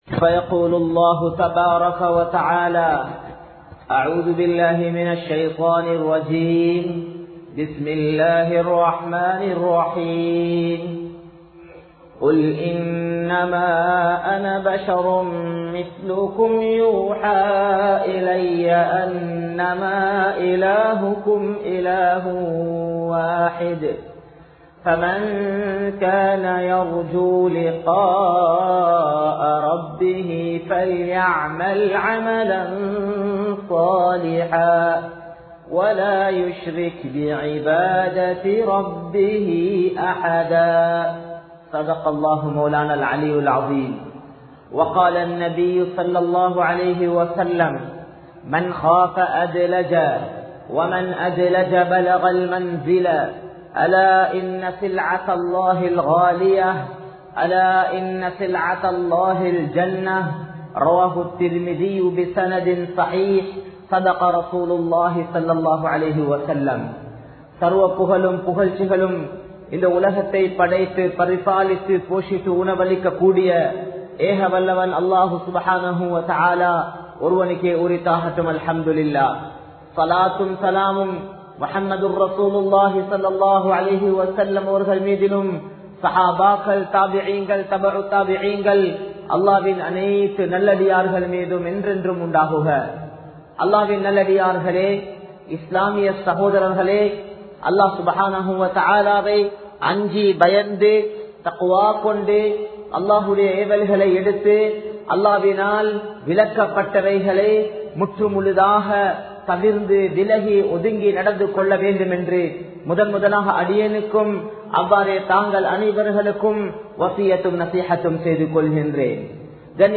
அல்லாஹ்வை நெருங்குவோம் | Audio Bayans | All Ceylon Muslim Youth Community | Addalaichenai
Jamiu Rawdha Jumma masjidh